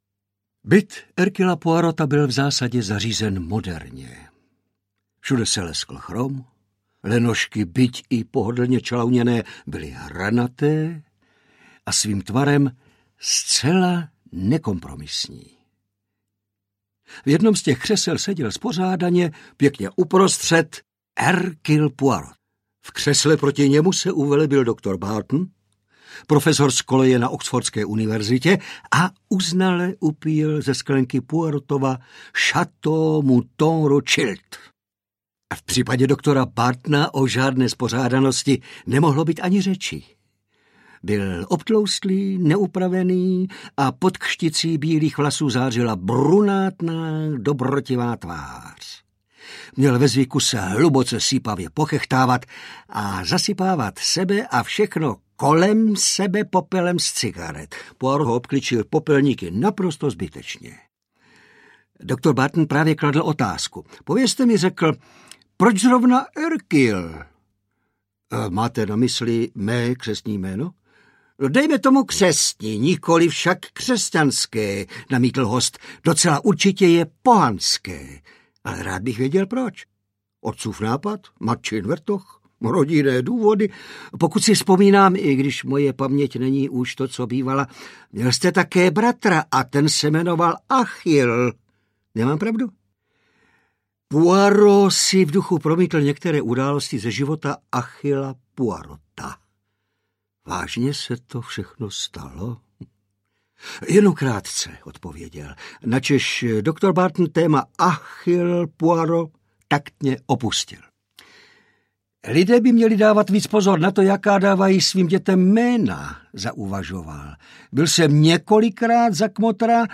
Herkulovské úkoly pro HERCULA POIROTA audiokniha
herkulovske-ukoly-pro-hercula-poirota-audiokniha